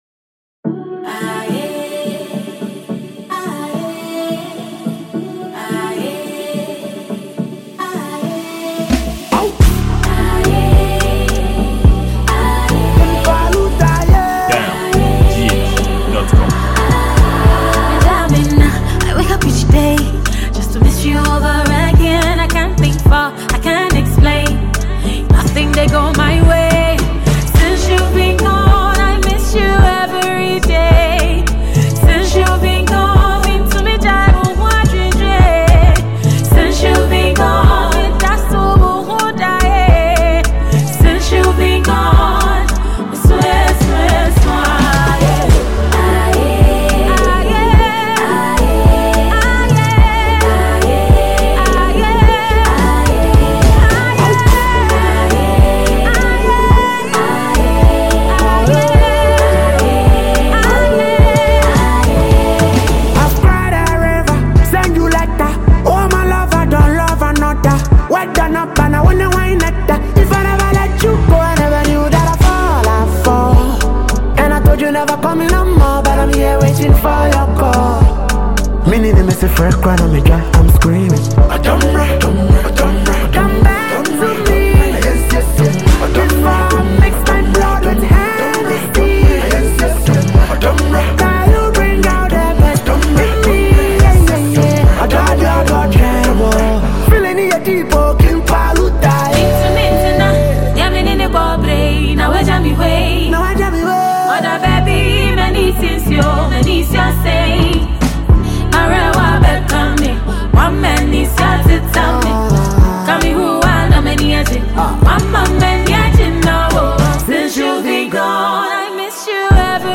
He is known for his witty lyrics and confident delivery.